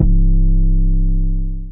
[808] mafia.wav